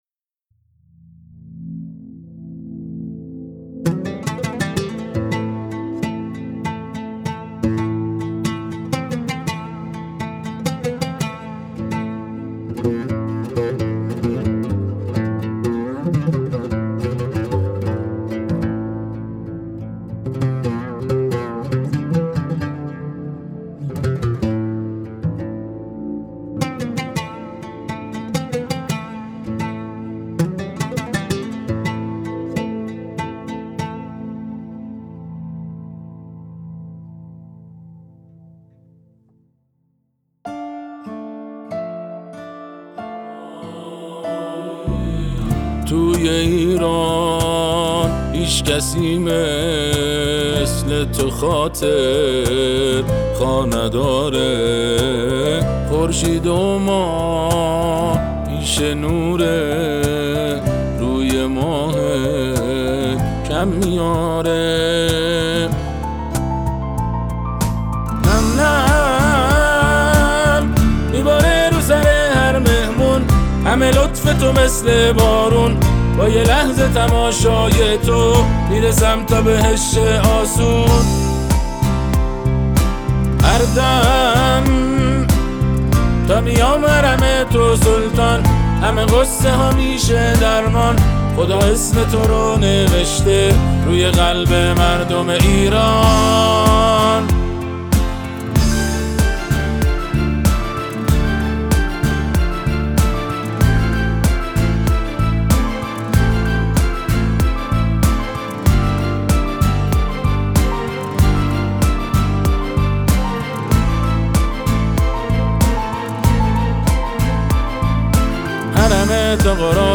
به مناسبت ولادت امام رضا(ع)
آثار آهنگین و آئینی